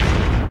mechstep.ogg